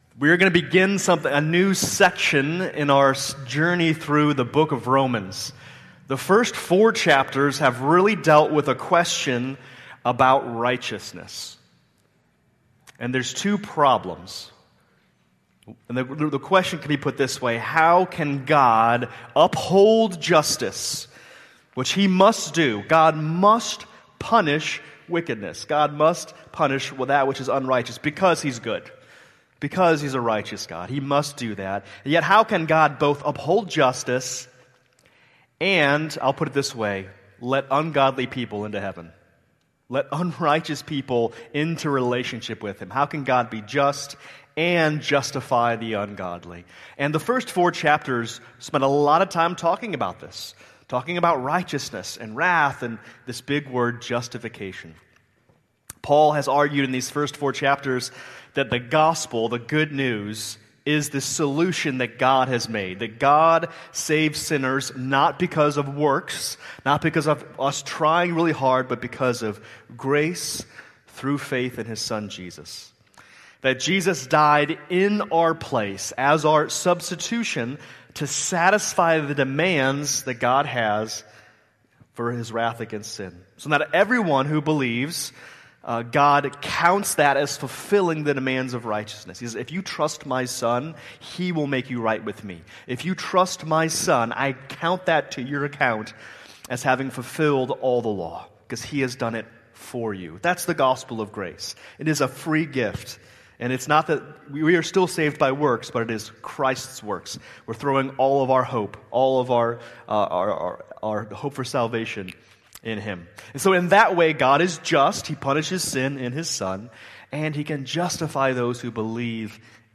November 9, 2005 Worship Service Order of Service: